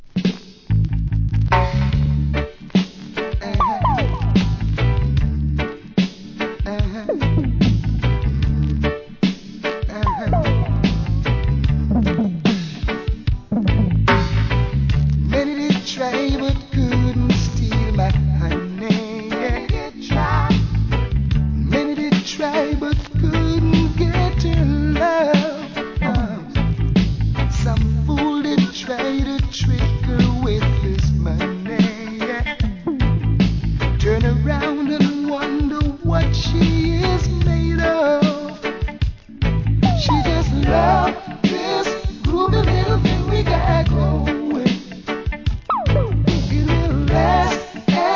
REGGAE
ベースにSWEET VOCALが最高に気持ちがイイ1985年名作!! 後半DUB接続!